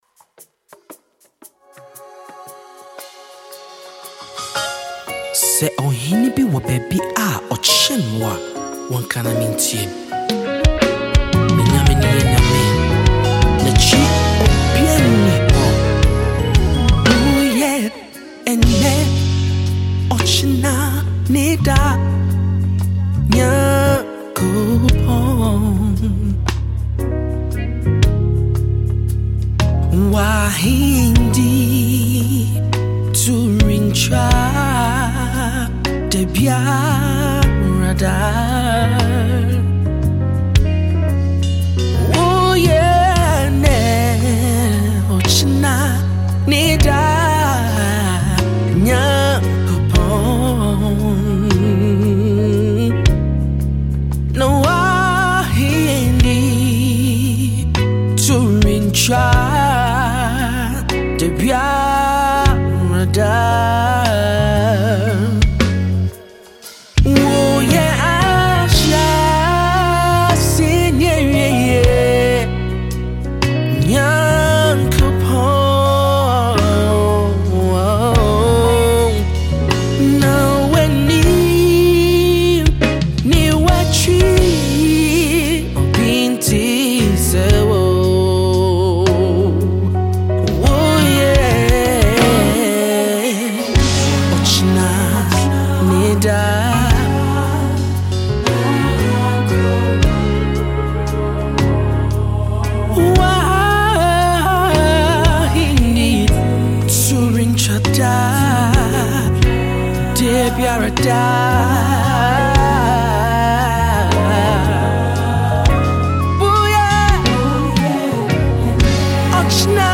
refreshing worship tune